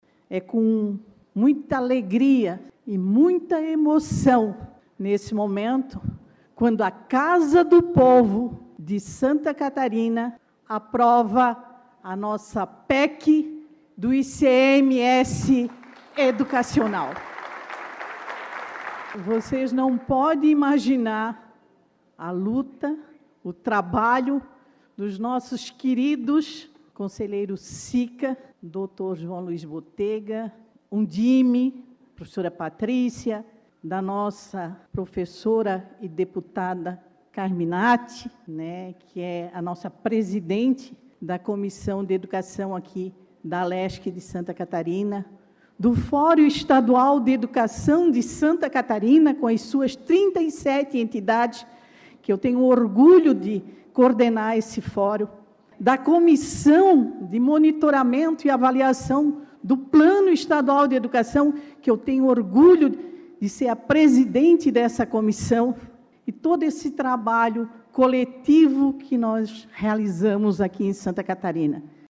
IV Sined e III Encontro de Promotores e Promotoras de Justiça da Educação - áudios dos participantes
Palestra "Educação e desigualdade no Brasil"